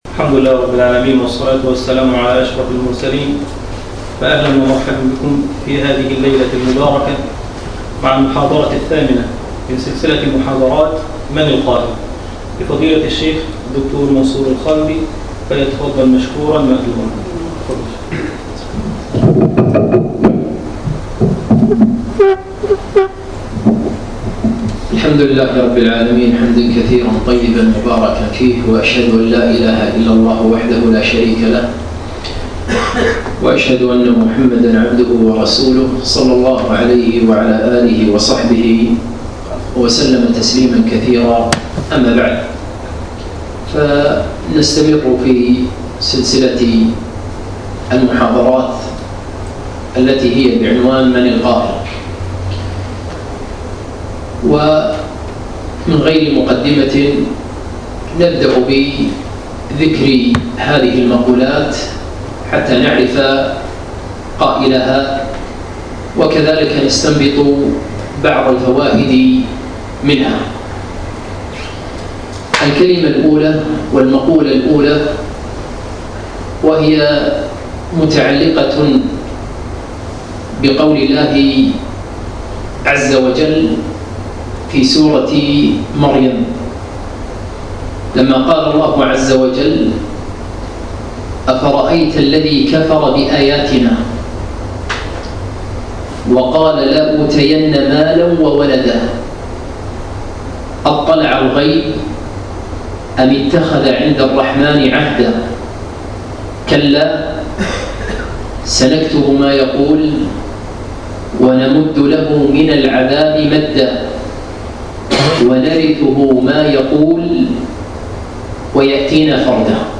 8- من القائل ؟ المحاضرة الثامنة